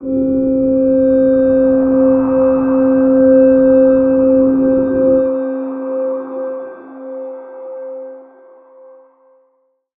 G_Crystal-C5-pp.wav